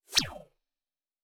Sci-Fi Sounds / Movement / Synth Whoosh 2_4.wav
Synth Whoosh 2_4.wav